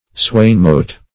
Search Result for " swainmote" : The Collaborative International Dictionary of English v.0.48: Swainmote \Swain"mote`\, n. [Swain + mote meeting: cf. LL. swanimotum.]